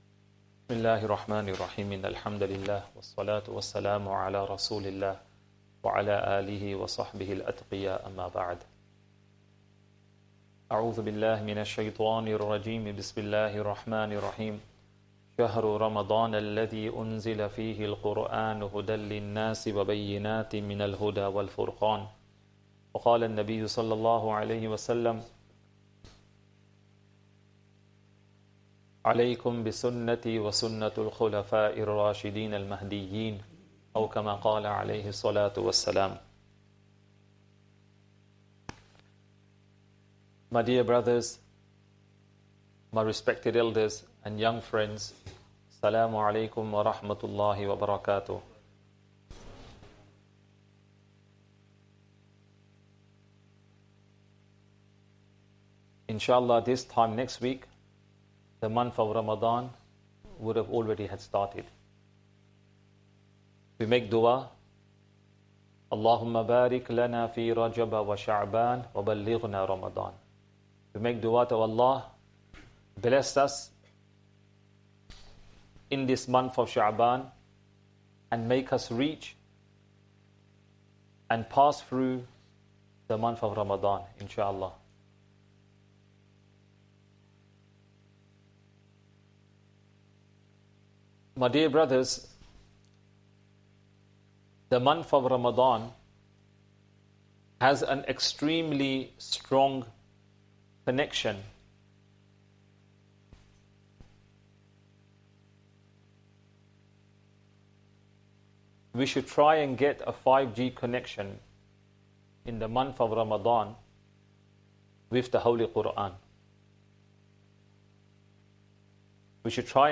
Masjid Adam | Jummah Talk and 2nd Khutbah | eMasjid Live
Jummah Talk and 2nd Khutbah